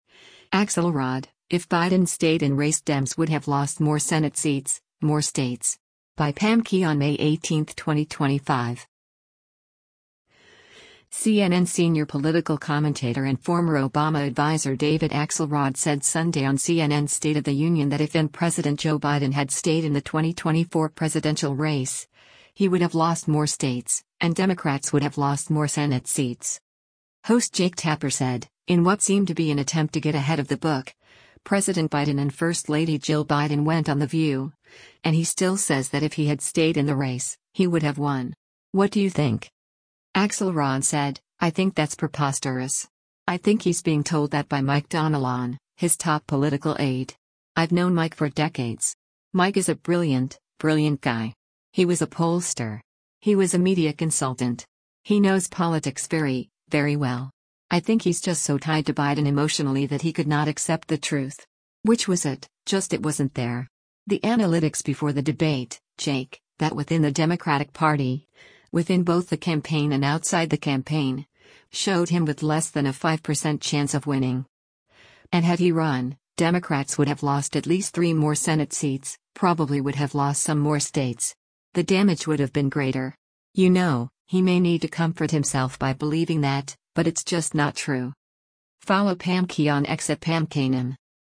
CNN senior political commentator and former Obama adviser David Axelrod said Sunday on CNN’s “State of the Union” that if then-President Joe Biden had stayed in the 2024 presidential race, he would have lost more states, and Democrats would have lost more Senate seats.